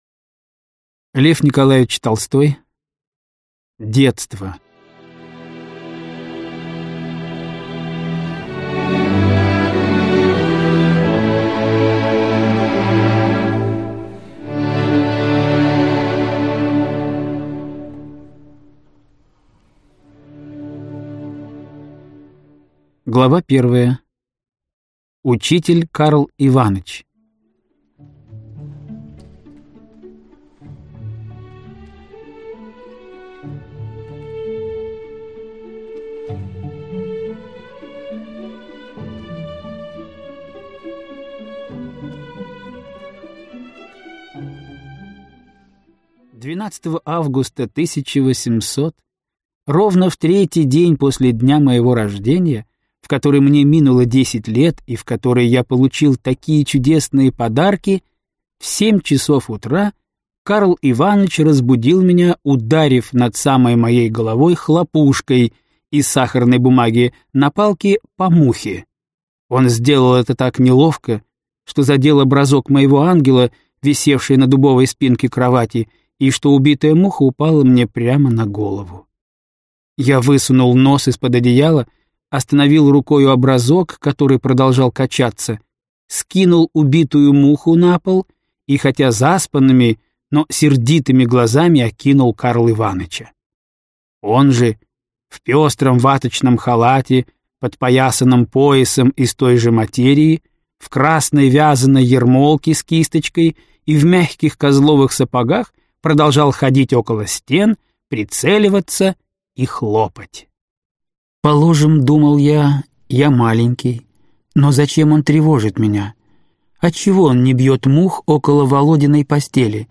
Аудиокнига Детство. Отрочество. Юность | Библиотека аудиокниг